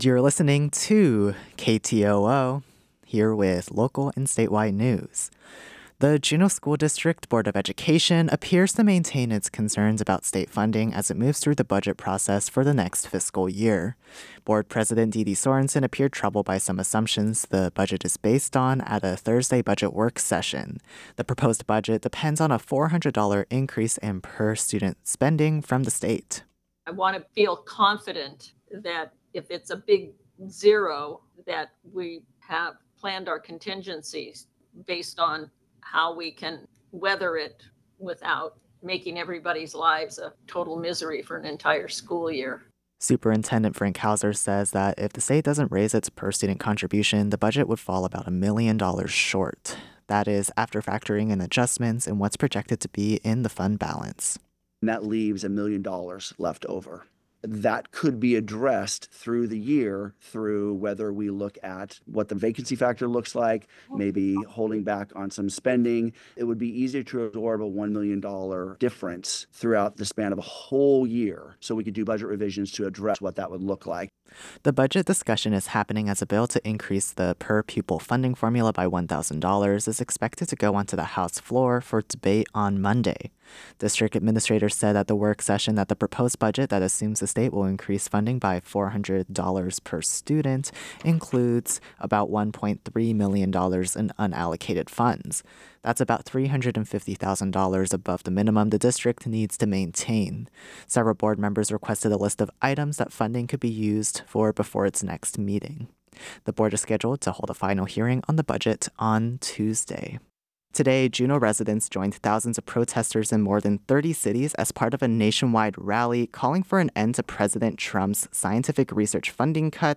Newscast – Friday, March 7, 2025 - Areyoupop